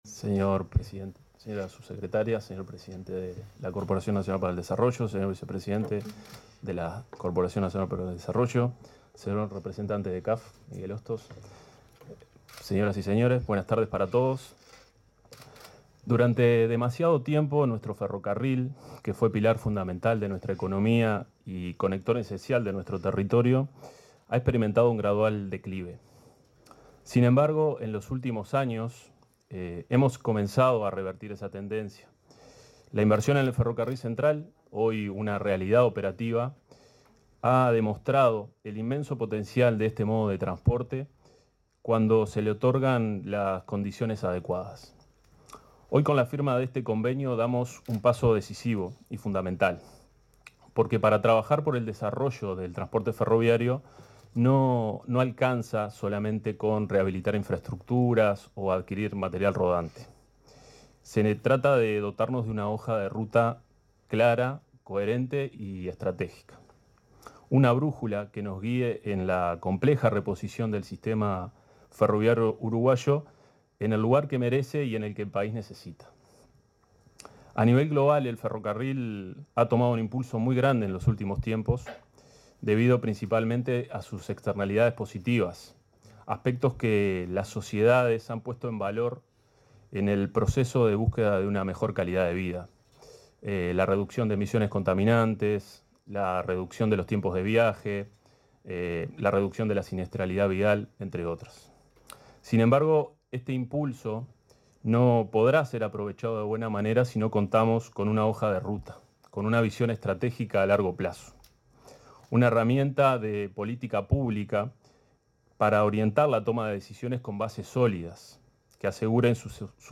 Palabras de autoridades en el MTOP